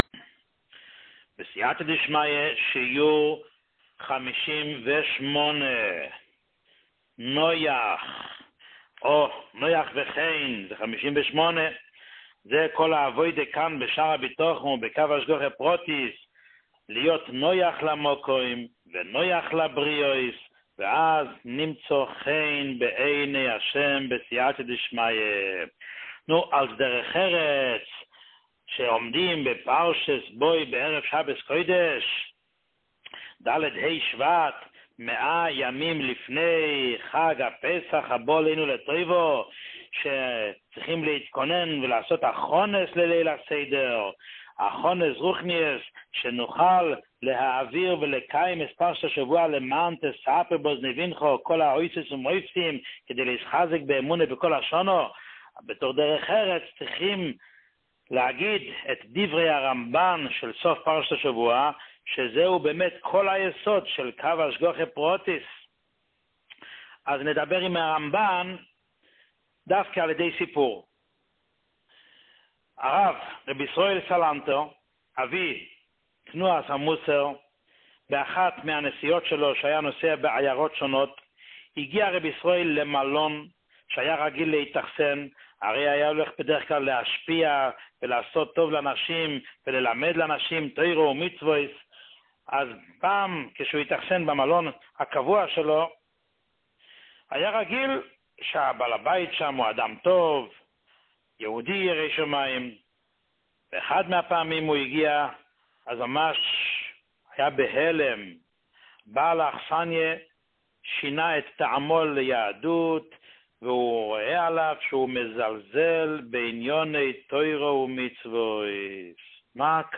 שיעורים מיוחדים
שיעור 58